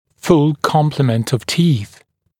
[ful ‘kɔmplɪmənt əv tiːθ][фул ‘комплимэнт ов ти:с]полный комплект зубов